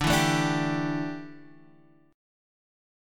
Dsus2sus4 chord